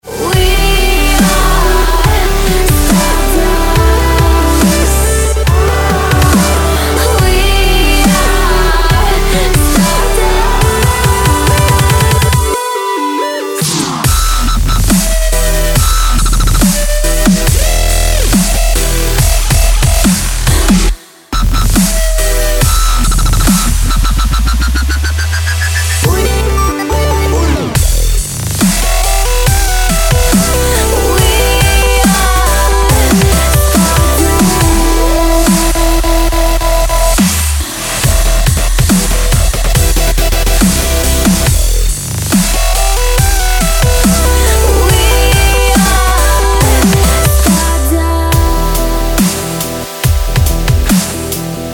DubStep / Дабстеп